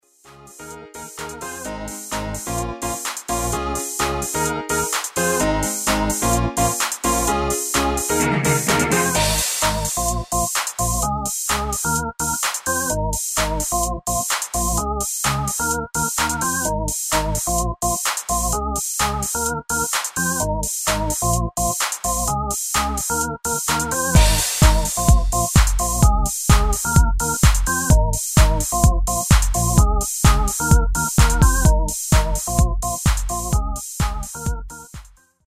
Demo/Koop midifile
Genre: Dance / Techno / HipHop / Jump
- Géén vocal harmony tracks